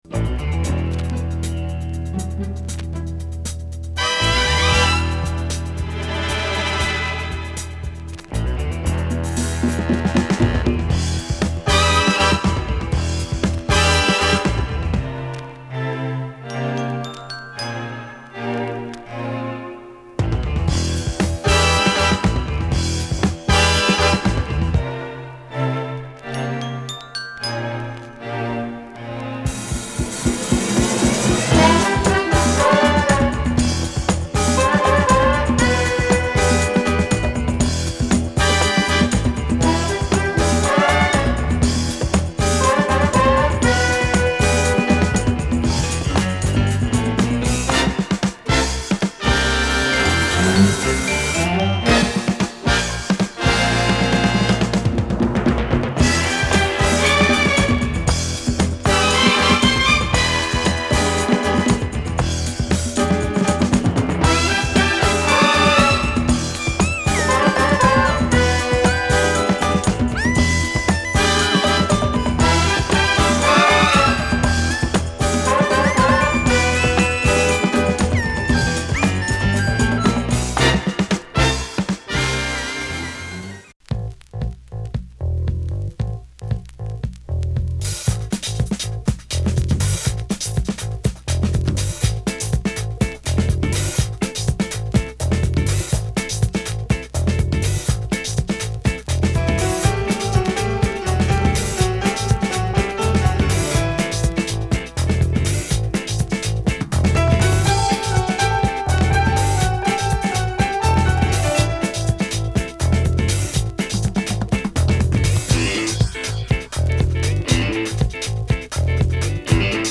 It is damn groovy out there !
afro funk rock
jazz groove with Asian touch
terrific pop groove sound with samples